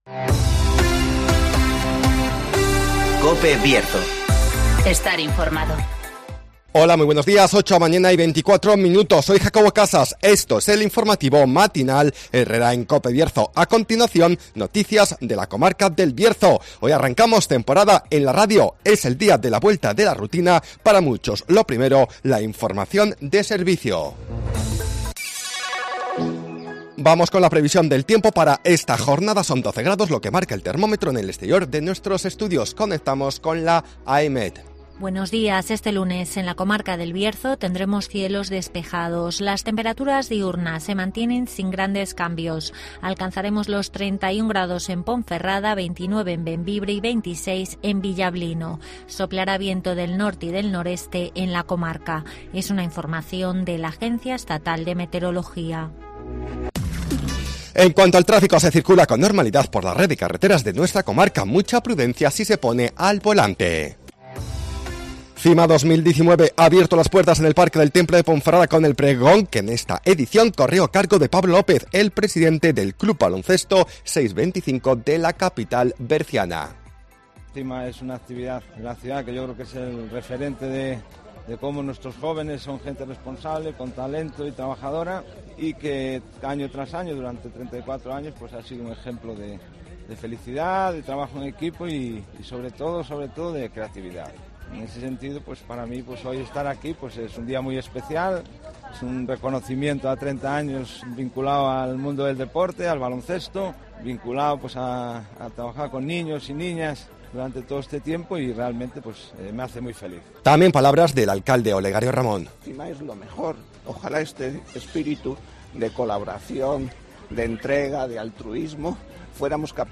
INFORMATIVOS
-Conocemos las noticias de las últimas horas de nuestra comarca, con las voces de los protagonistas